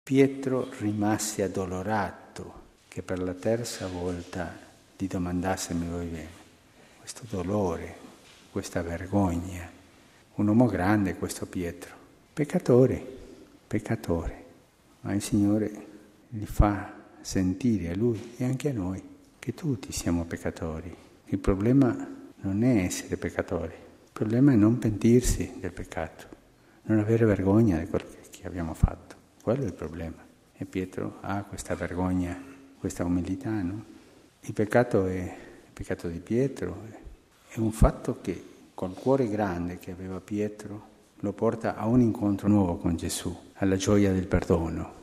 Det sa påven sammanfattningsvis i fredagsmorgonens mässa i Santa Martakapellet, där några anställda vid Vatikanmuséerna deltog.